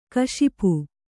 ♪ kaśipu